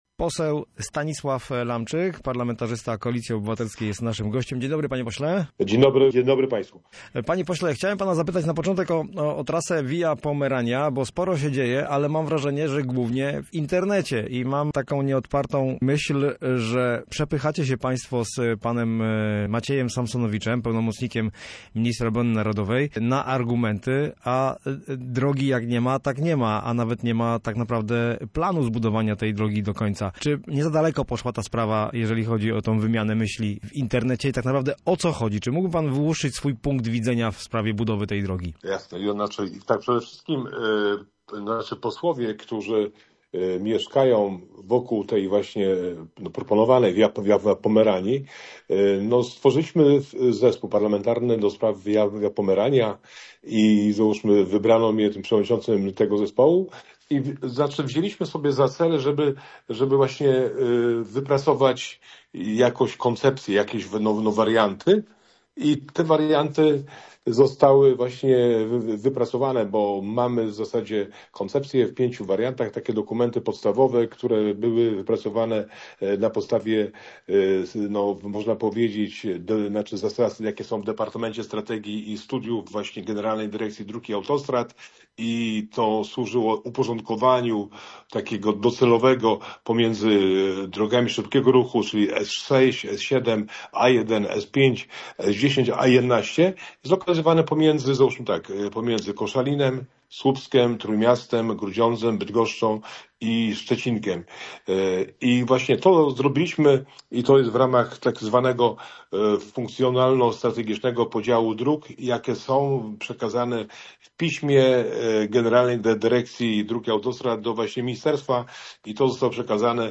Posłuchaj rozmowy z posłem Stanisławem Lamczykiem: https